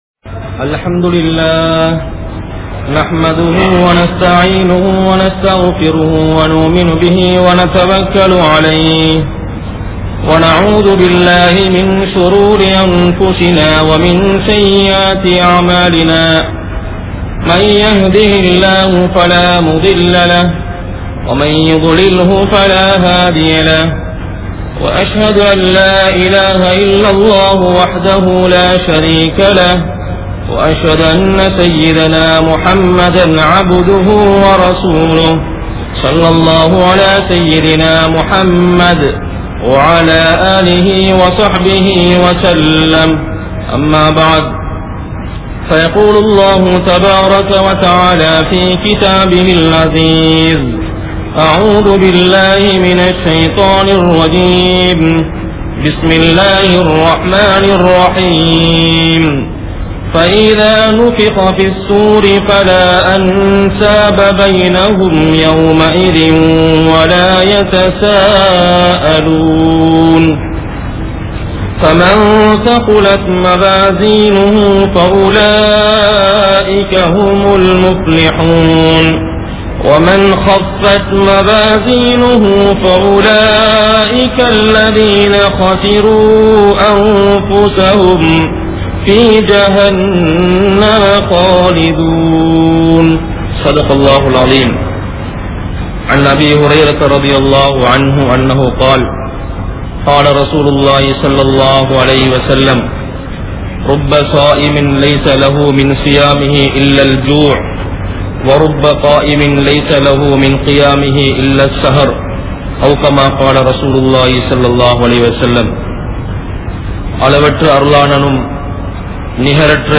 Amalhal | Audio Bayans | All Ceylon Muslim Youth Community | Addalaichenai